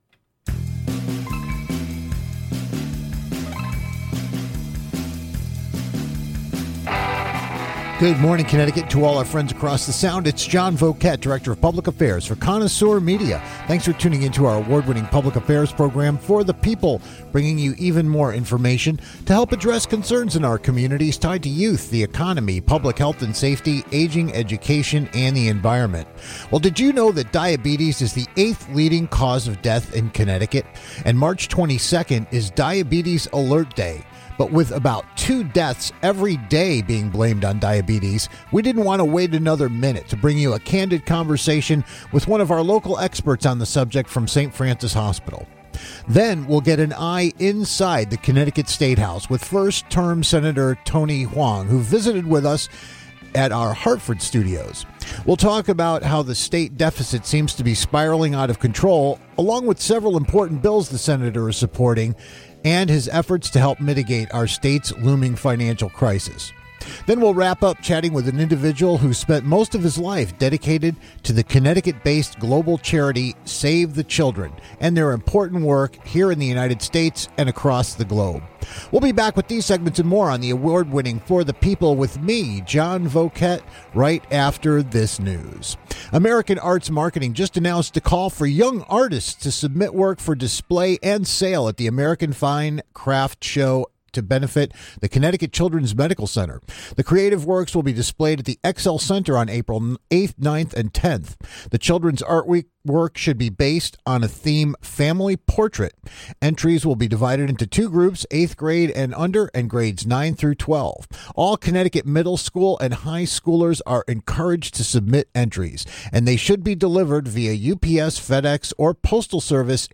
Then we'll check-in with first-term State Senator Tony Hwang to get an eye inside the statehouse as lawmakers grapple with an out of control state deficit. And we'll wrap getting a front line perspective on one of the world's most widely recognized human service charities - Connecticut-based Save the Children.